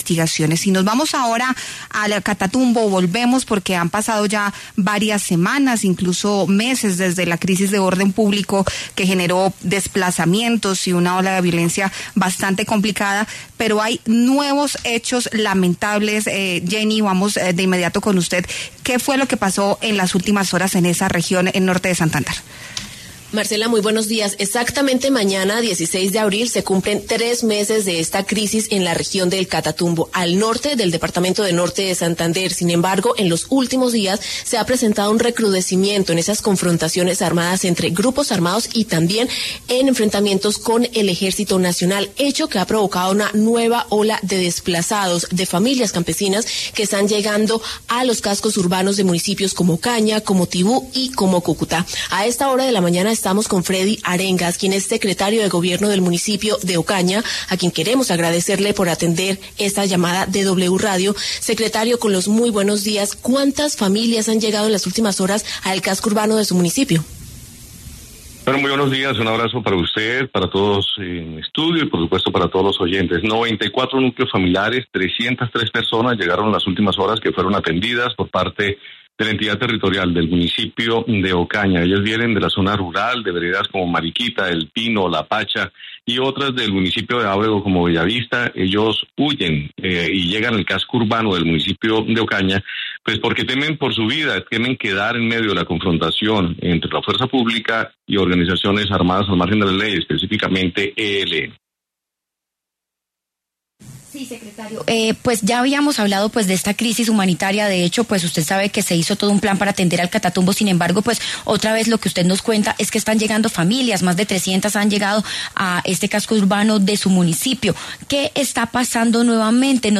Freddy Arengas, secretario de Gobierno de Ocaña, reveló en La W que 303 personas han sido atendidas en medio de esta crisis.